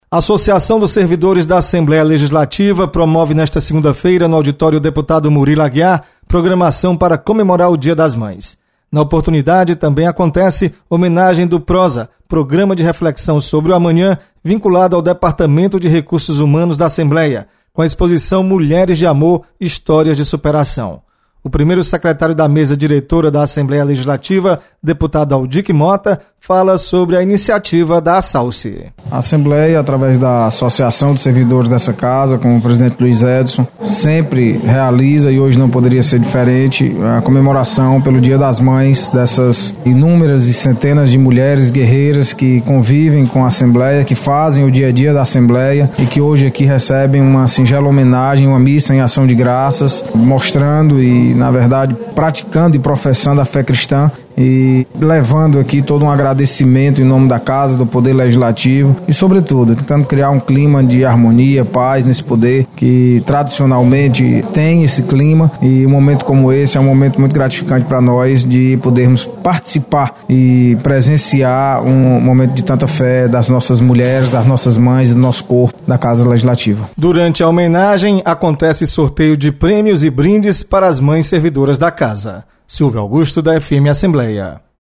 Assalce realiza festa em homenagem às mães. Repórter